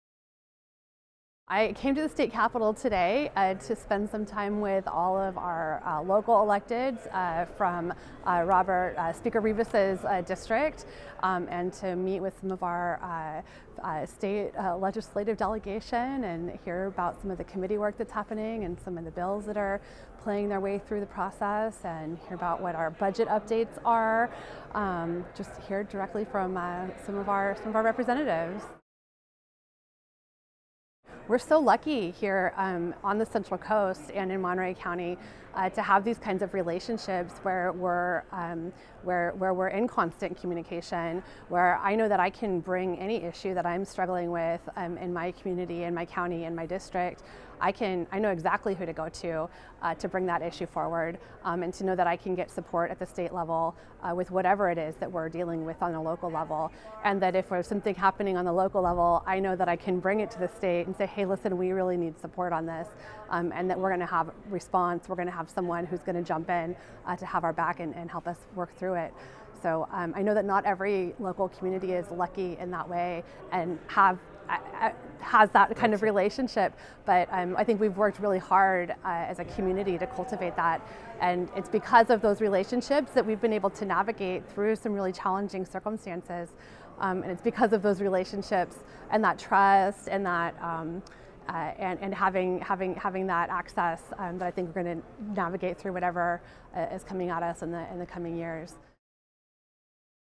For radio news outlets who would like to air this story, the following links are soundbites of the State Legislative Issues Day in English and Spanish
Wendy Root Askew, Monterey County Board of Supervisors (two cuts) 1:40